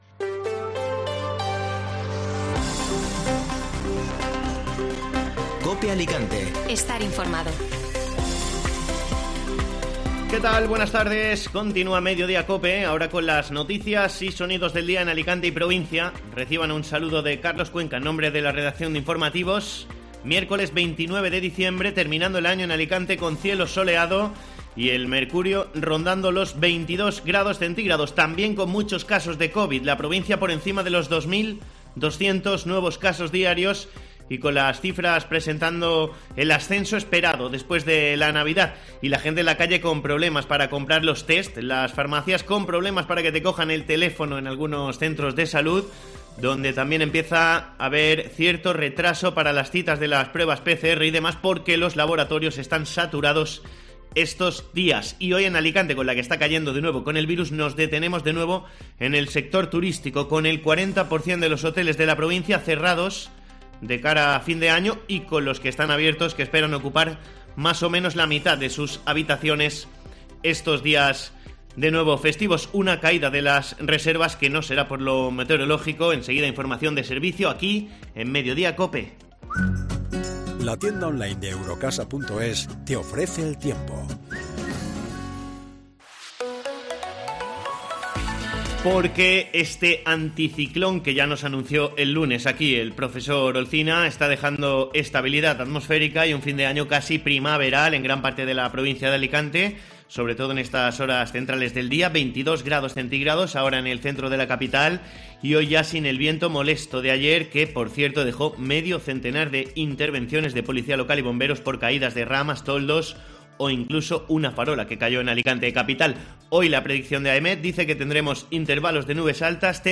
Informativo Mediodía COPE (Miércoles 29 de diciembre)